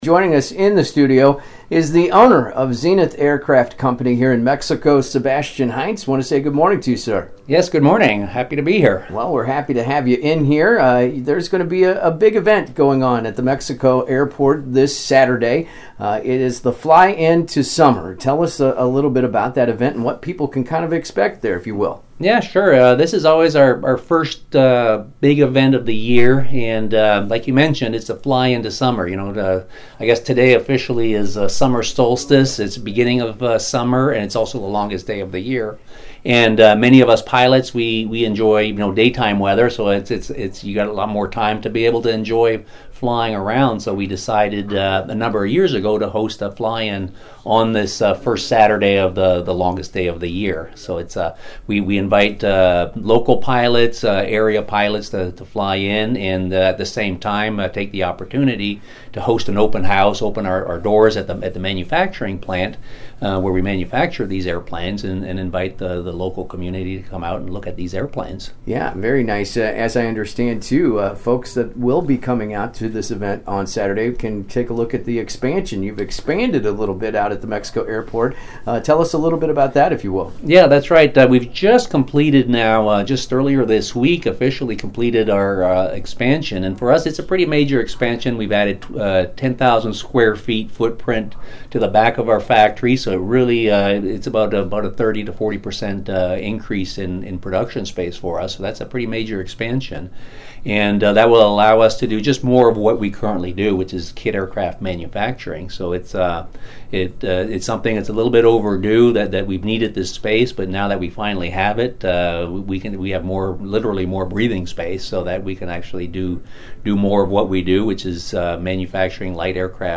zenith-interview.mp3